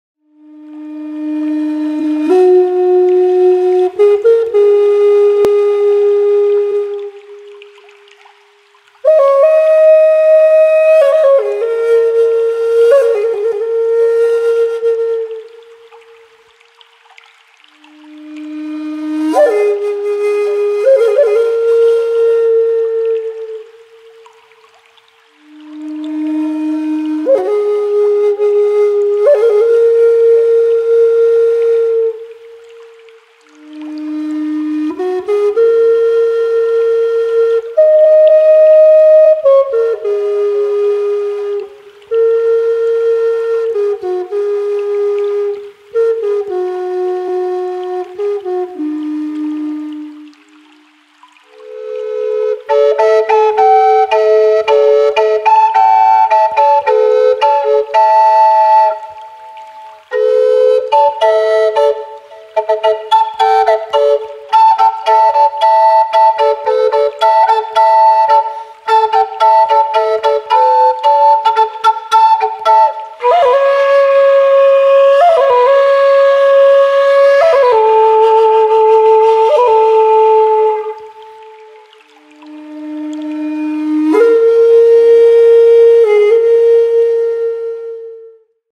native-american-flute-sample-pack.mp3